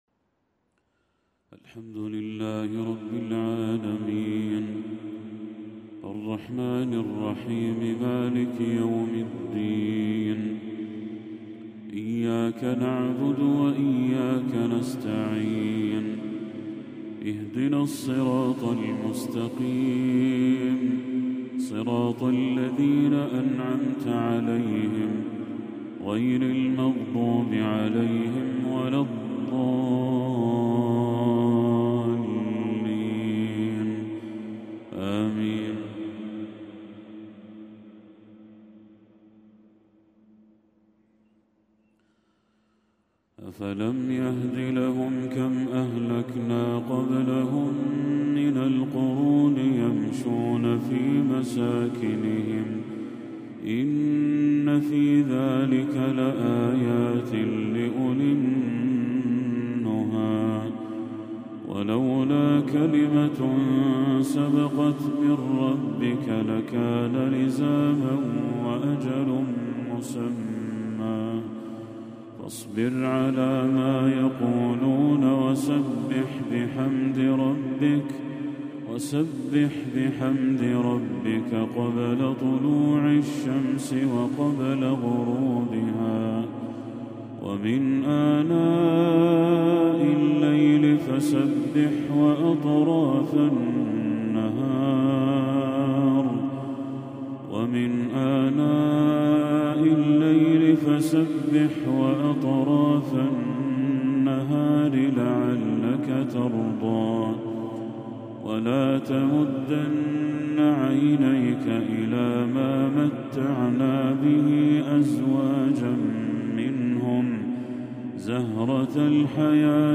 تلاوة جميلة لخواتيم سورتي طه والمؤمنون
عشاء 24 ربيع الأول 1446هـ